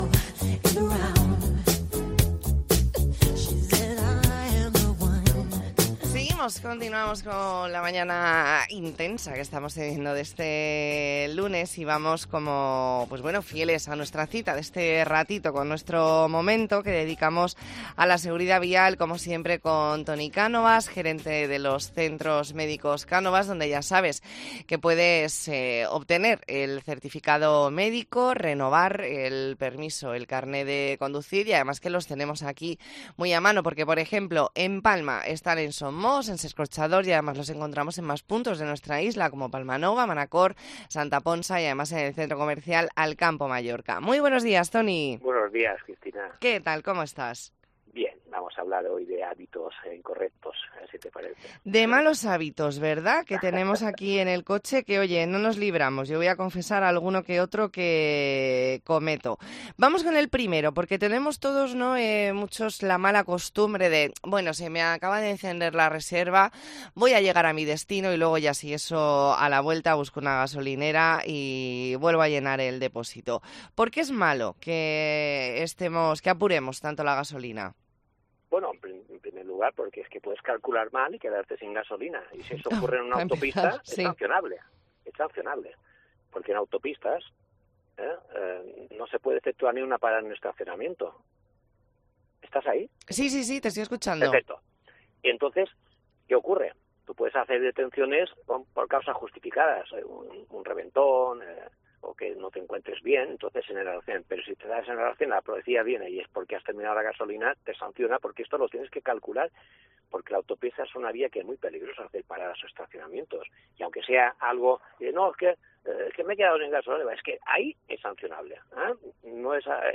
Entrevista en La Mañana en COPE Más Mallorca, lunes 30 de octubre de 2023.